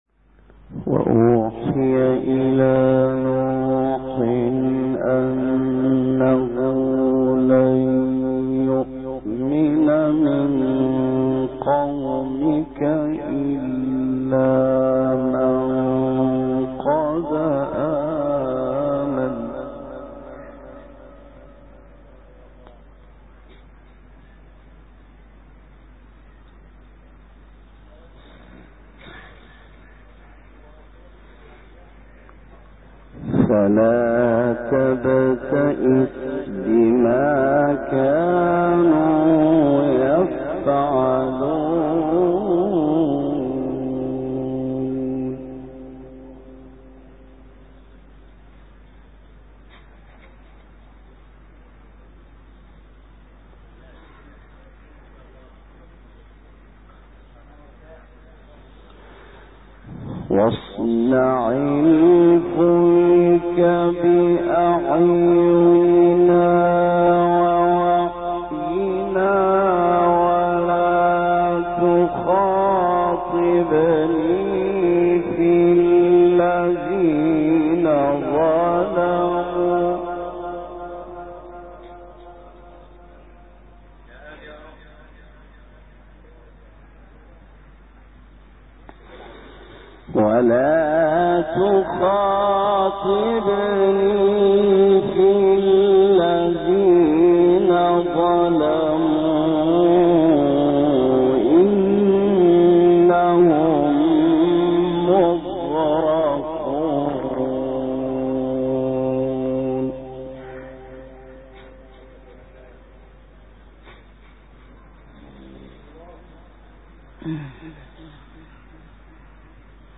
آوای قرآن _ دانلود تلاوت قرآن - سوره ,استاد ,مقام.mp3